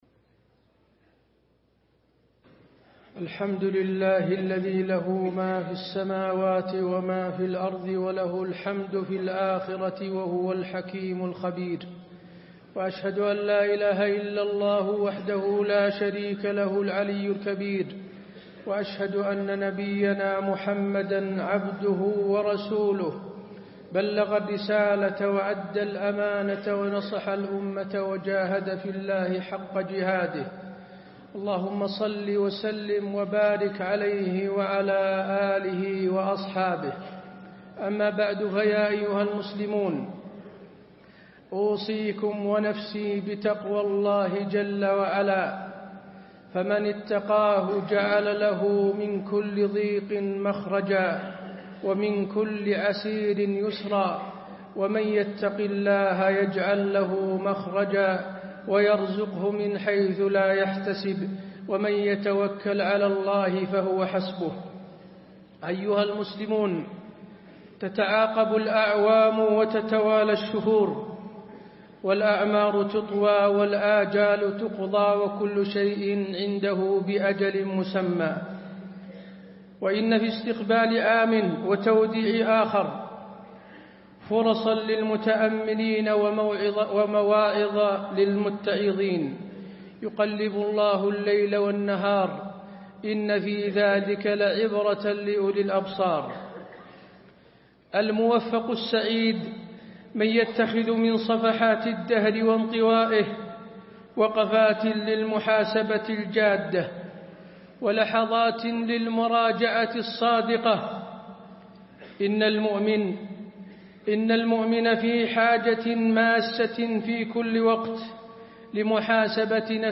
تاريخ النشر ٢٧ ذو الحجة ١٤٣٤ هـ المكان: المسجد النبوي الشيخ: فضيلة الشيخ د. حسين بن عبدالعزيز آل الشيخ فضيلة الشيخ د. حسين بن عبدالعزيز آل الشيخ العام الجديد وآلام الأمة